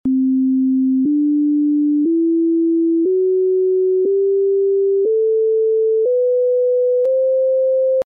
écoute des 7 notes correspondant aux 7 premières fractions .
La 4ème note peut heurter par rapport à nos habitudes ; remarquons que c’est la fraction la plus " compliquée " qui est en cause ; on peut considérer qu’il faut qu’elle soit plus grave, ce qui impose de choisir une fraction plus grande ; 3/4 est la fraction la plus simple qui respecte ce contrat ; notons que 2/3 de 3/4 redonnent 1/2 , mais surtout :